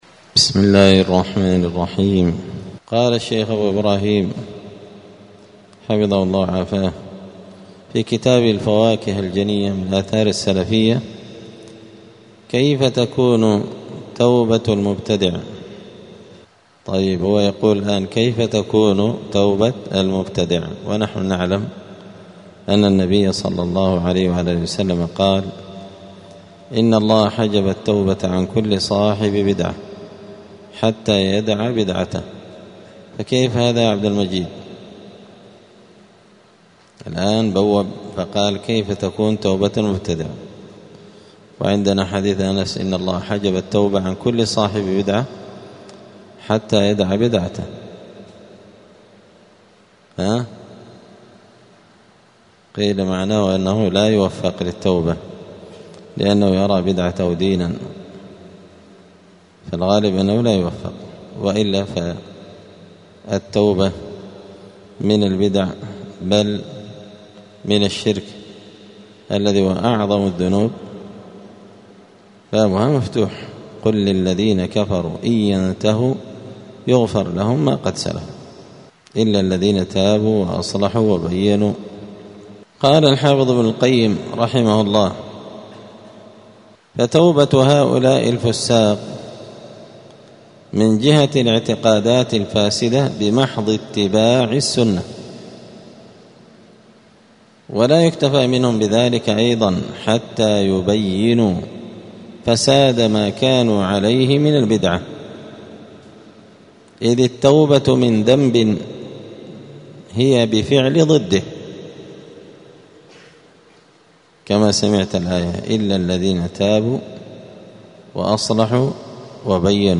دار الحديث السلفية بمسجد الفرقان بقشن المهرة اليمن